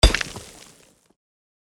Mining_2.mp3